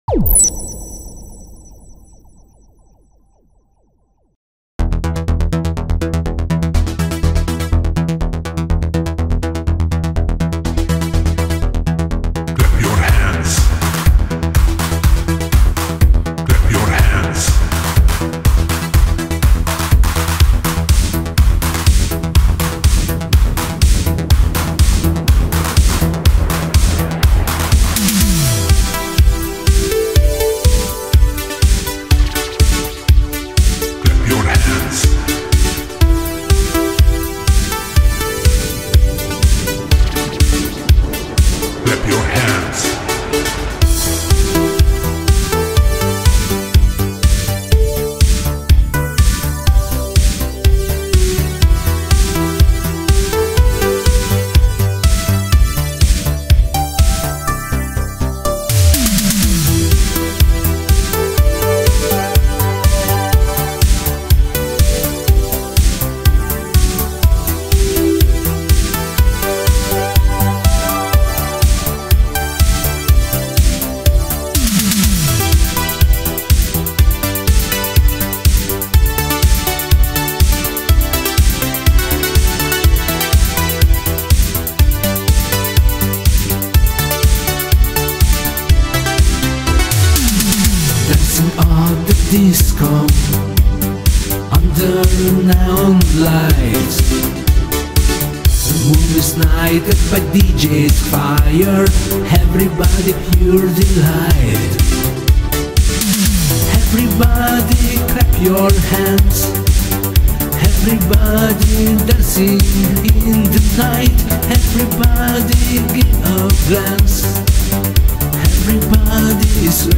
New italo disco, бодренько так))